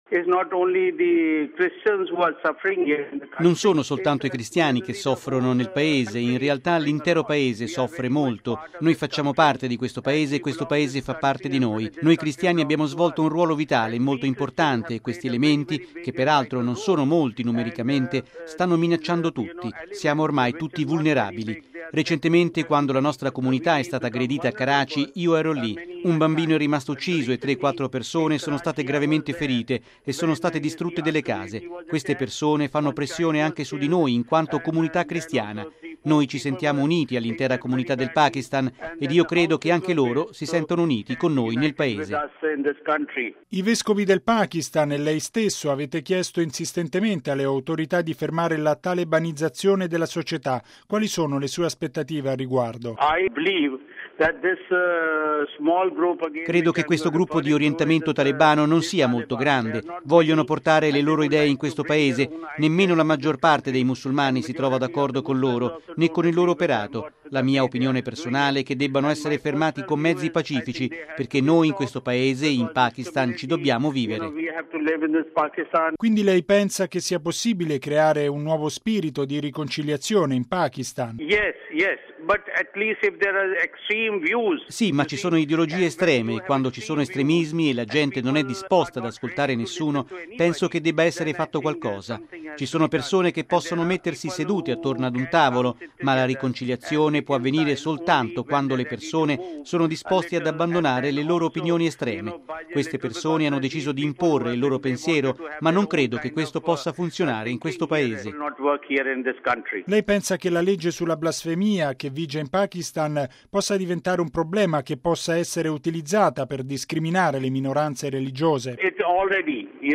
R. – It’s not only the Christians who are suffering in the Country: …